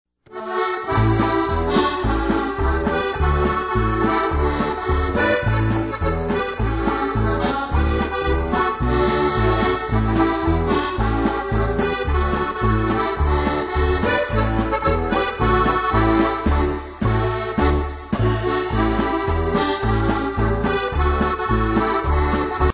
Cleveland Style (Slovenian)